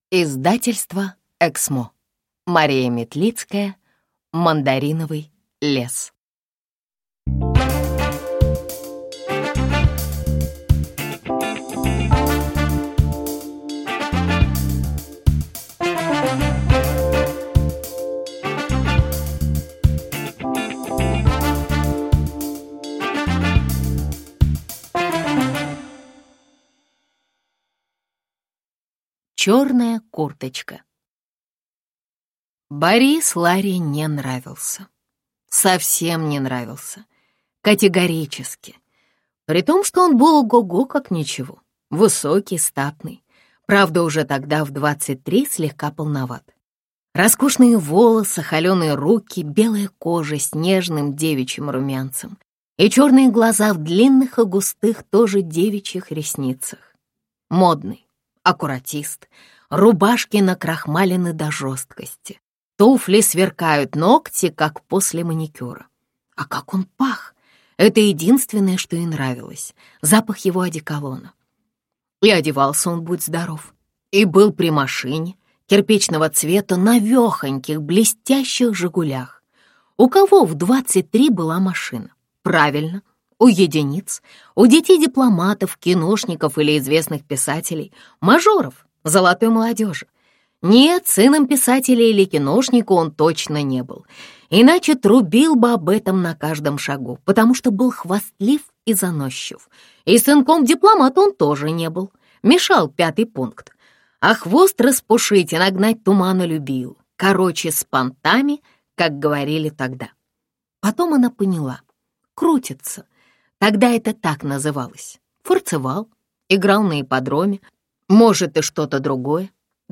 Аудиокнига Мандариновый лес | Библиотека аудиокниг